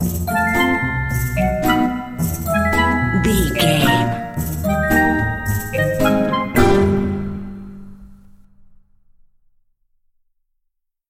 Diminished
flute
oboe
strings
orchestra
cello
double bass
percussion
circus
comical
cheerful
perky
Light hearted
quirky